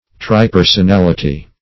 Search Result for " tripersonality" : The Collaborative International Dictionary of English v.0.48: Tripersonality \Tri*per`son*al"i*ty\, n. The state of existing as three persons in one Godhead; trinity.
tripersonality.mp3